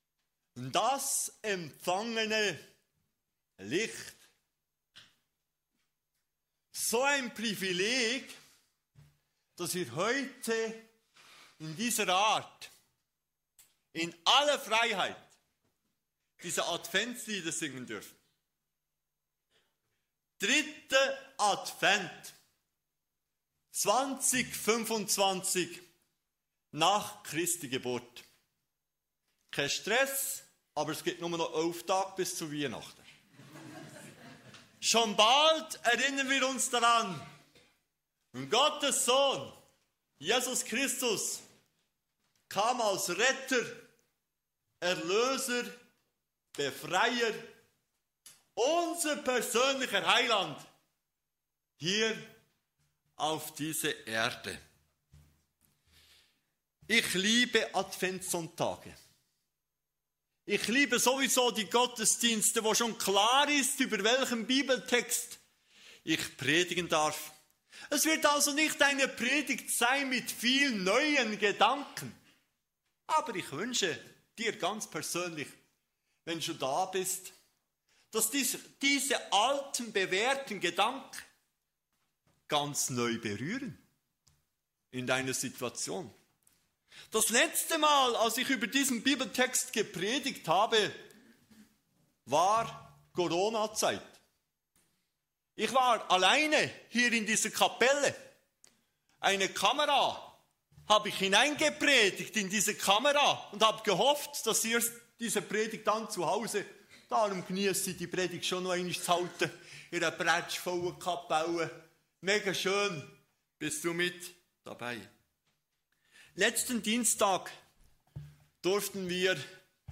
Kategorie: Adventsgottesdienst